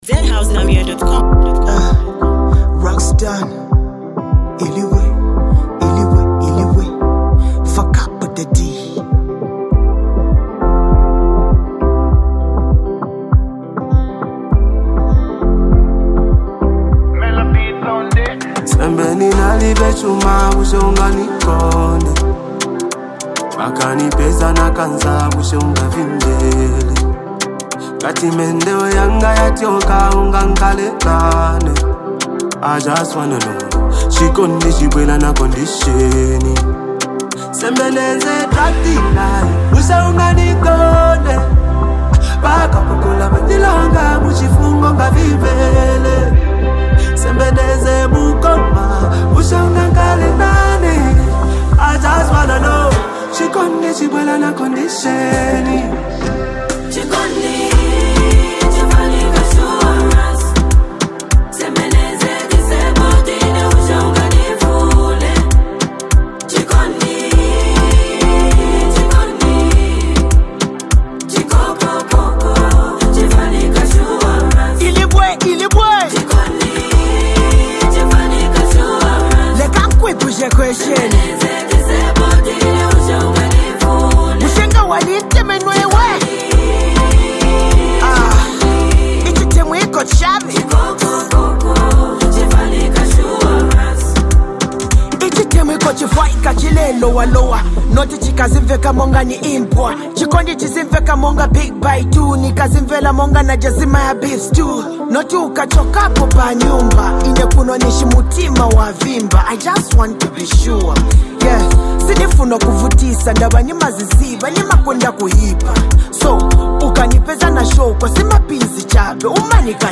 A soulful track you’ll want on repeat!